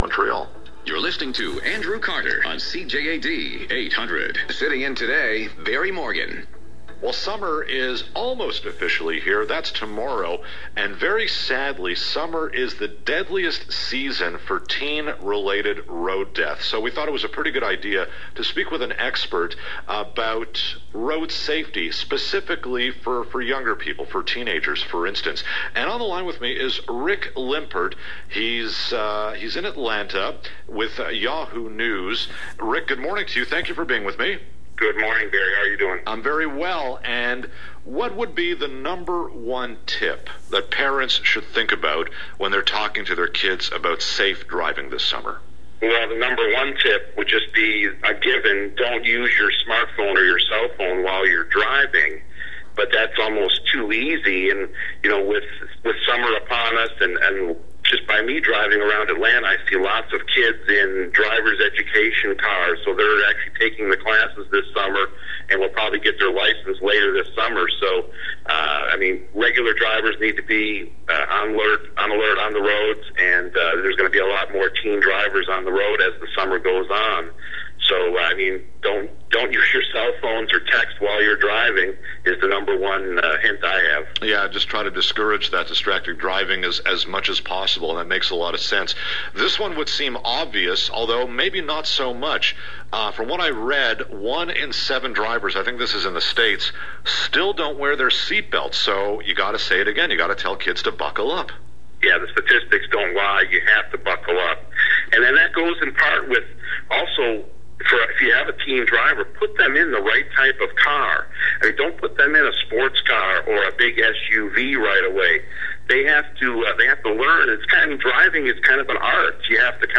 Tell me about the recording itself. CJAD 800 AM radio in Montreal